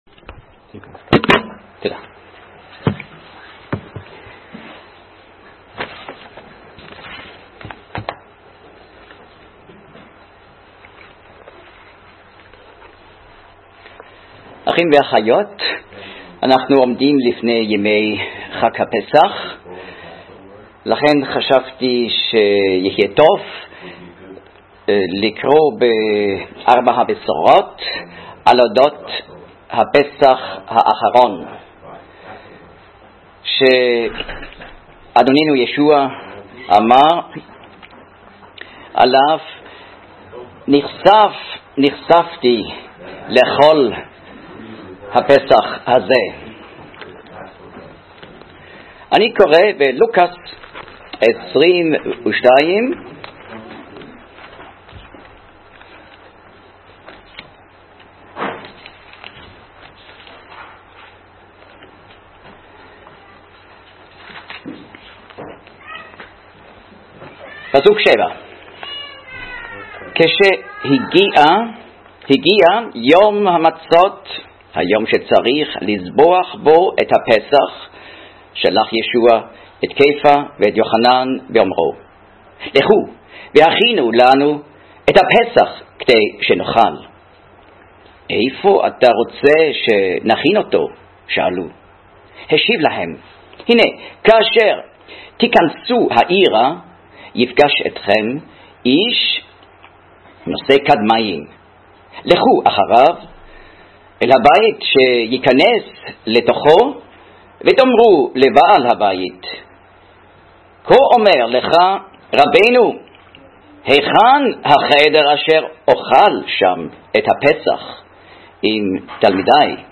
דצמבר 24, 2019 דרשות לפי נושאים